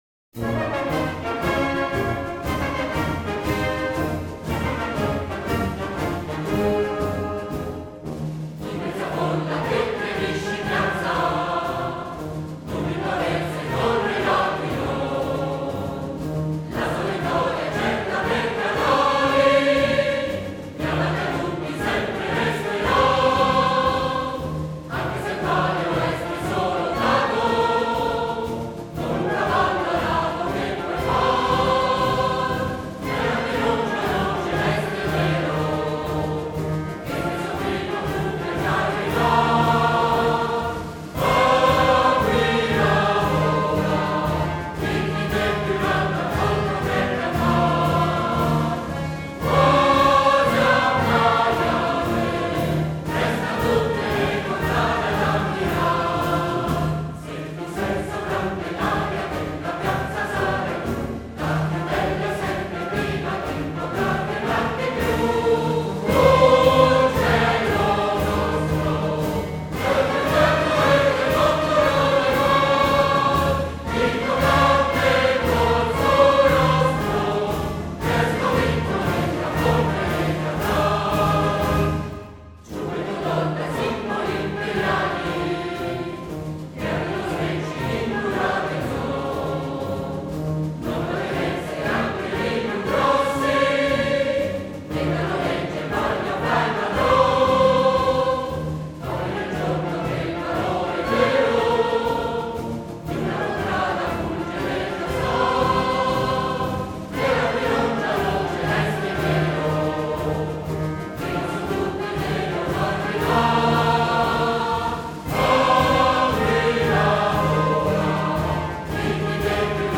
Versi e musica del Maestro Carlo Sottili – E’ un inno in 6/8 di stile “fanfaristico” con prevalenza di ottoni.